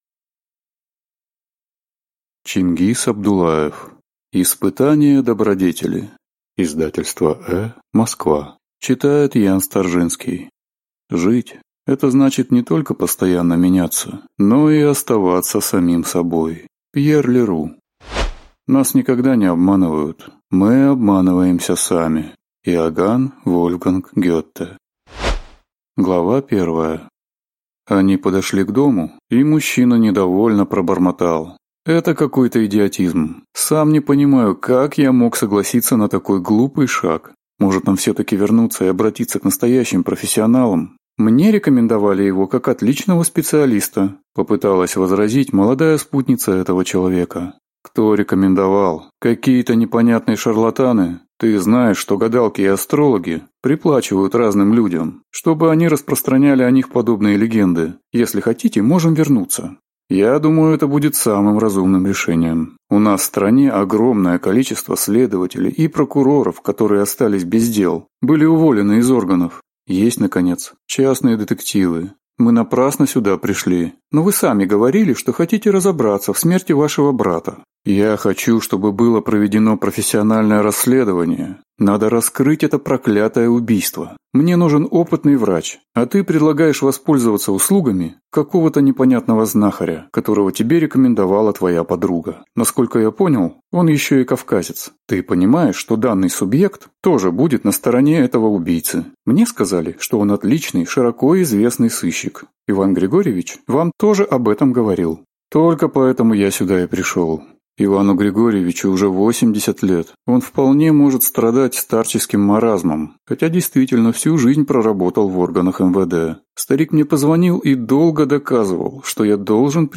Аудиокнига Испытание добродетели | Библиотека аудиокниг